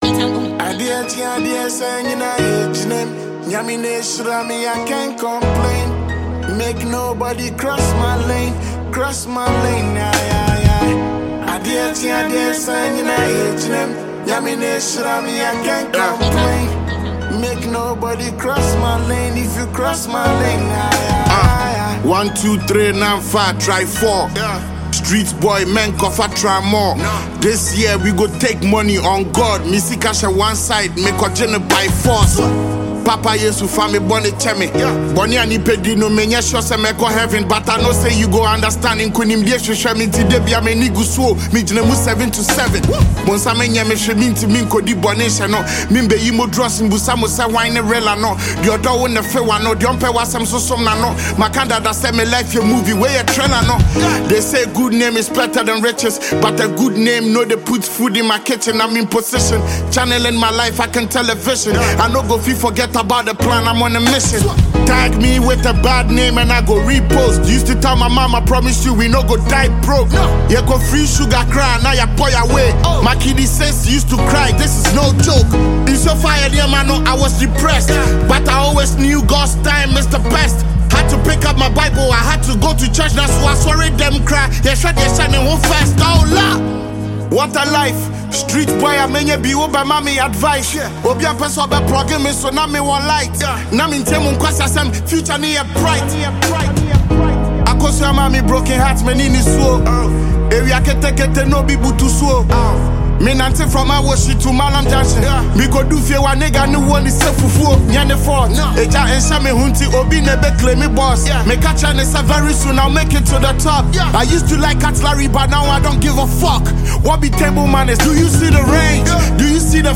Heavyweight Ghanaian rapper